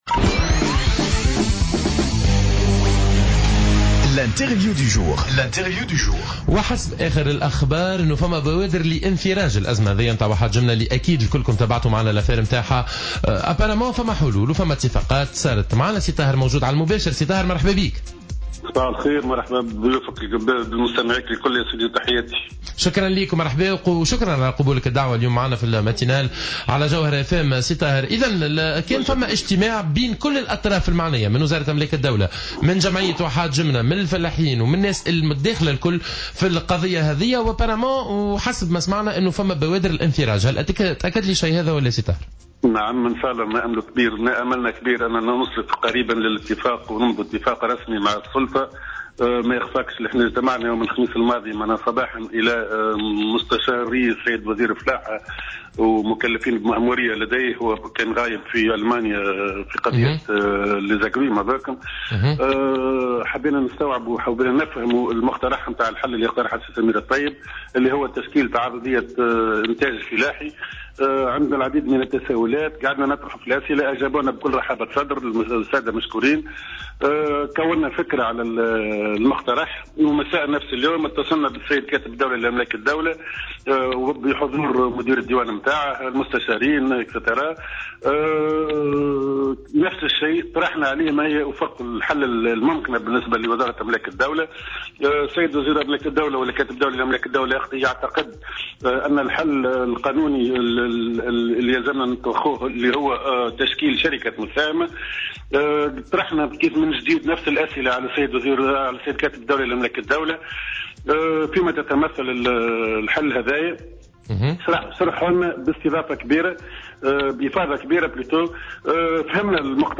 Intervenu ce matin sur les ondes de Jawhara FM